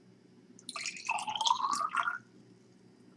PourLiquid.wav